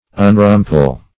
Search Result for " unrumple" : The Collaborative International Dictionary of English v.0.48: Unrumple \Un*rum"ple\, v. t. [1st pref. un- + rumple.] To free from rumples; to spread or lay even, [1913 Webster]